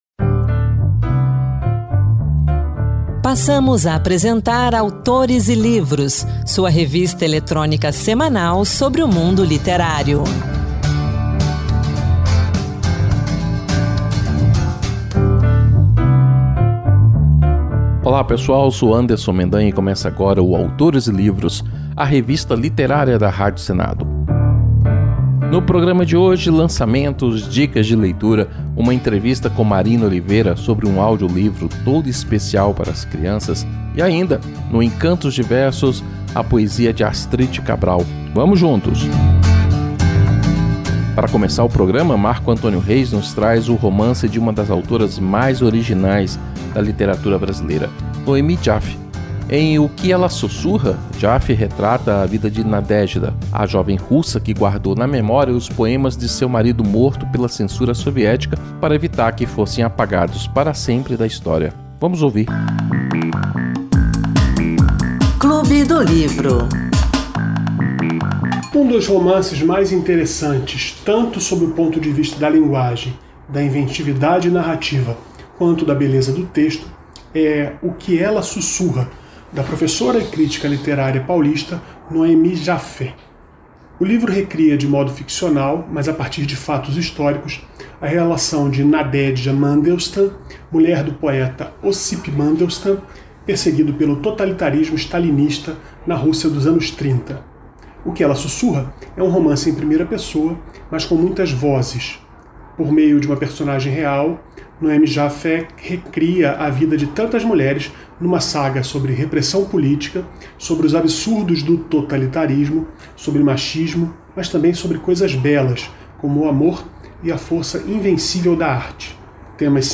uma entrevista